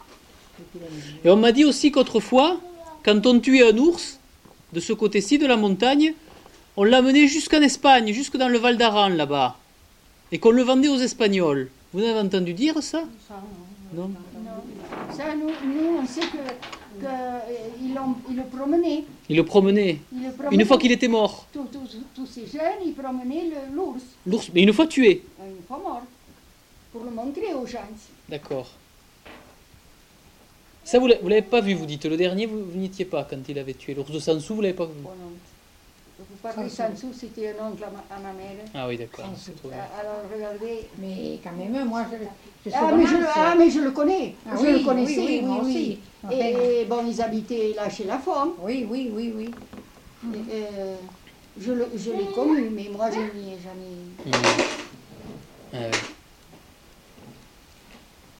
Aire culturelle : Couserans
Lieu : Montjoie-en-Couserans
Genre : témoignage thématique